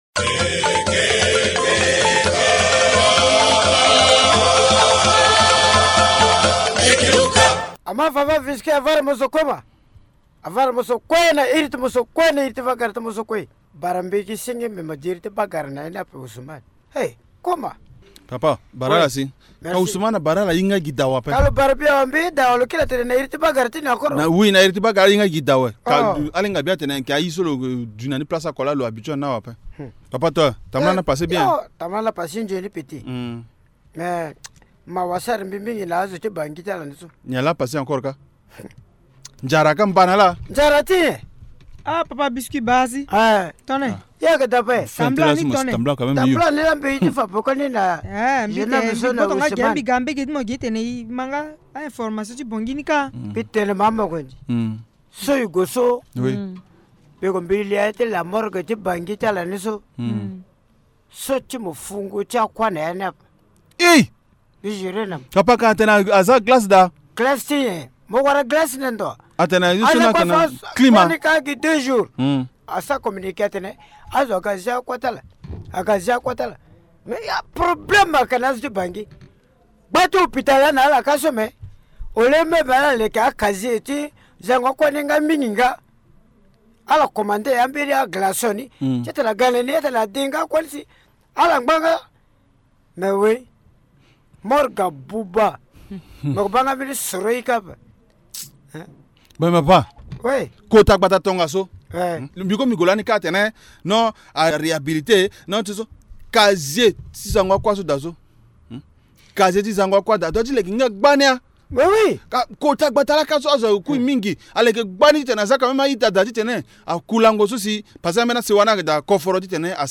La Troupe Linga Théâtre interpelle l’attention des autorités nationale sur la problématique des morgues en Centrafrique. Aujourd’hui, le pays ne dispose que de deux morgues pour l’ensemble du territoire avec une capacité insuffisante. Pour les comédiens du village Linga, la création des morgues privées pourrait être une solution.